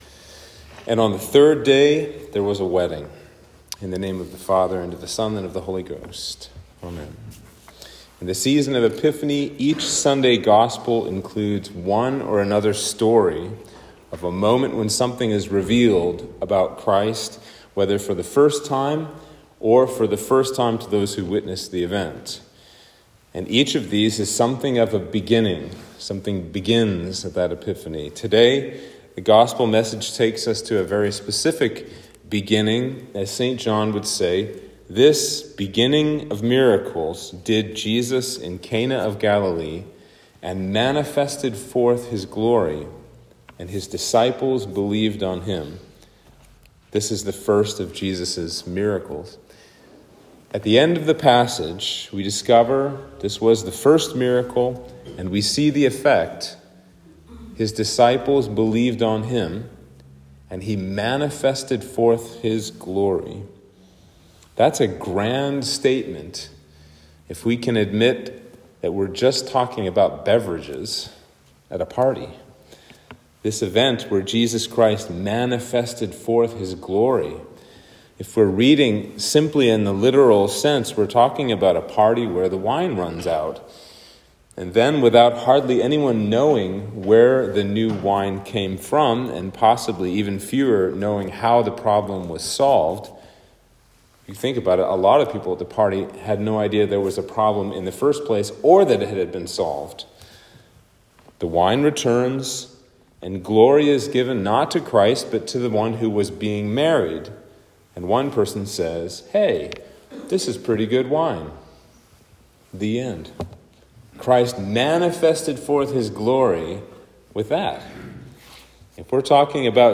Sermon for Epiphany 3